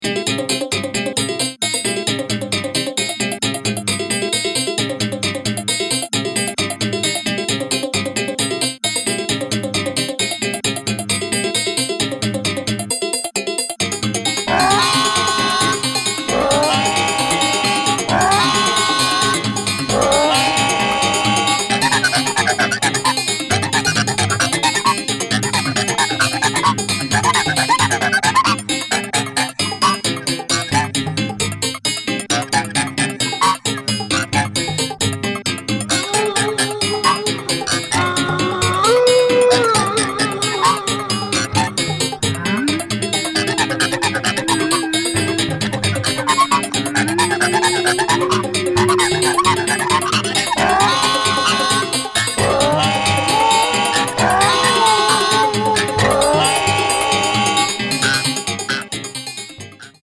明石とベルリンの遠隔セッションで制作されたという本作